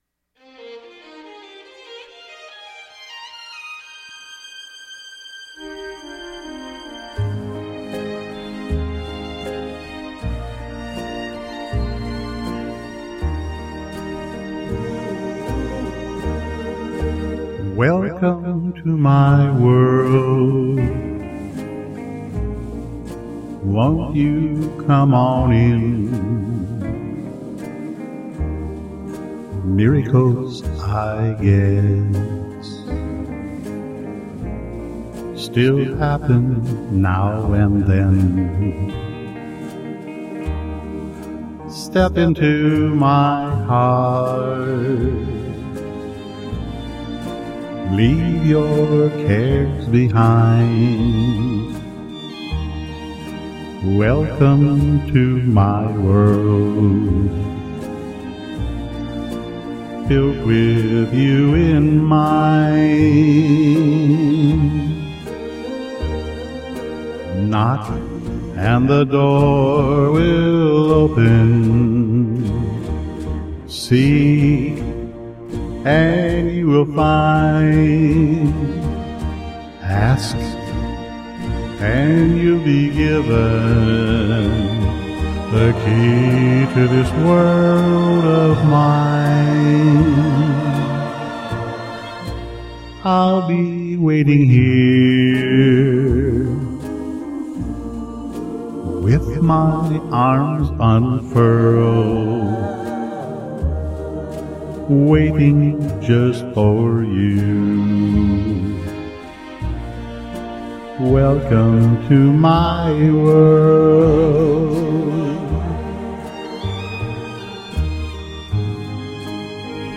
song cover